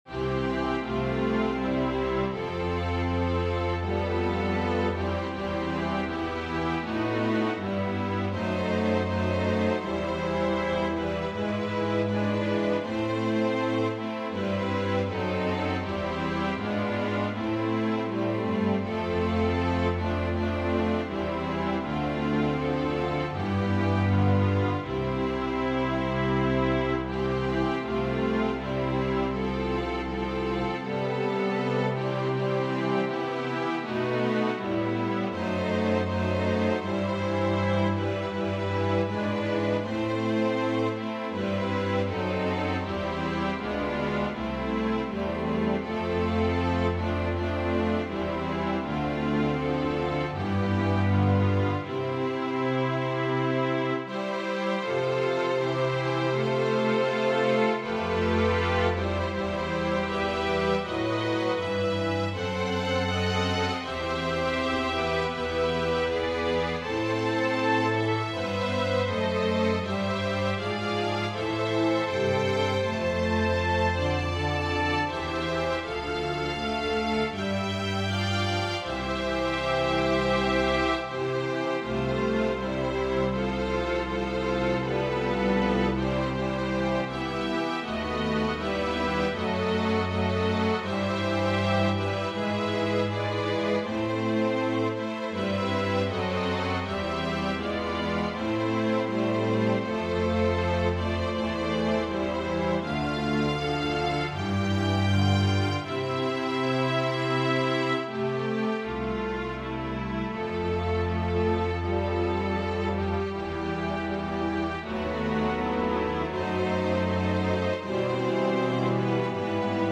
Organ/Organ Accompaniment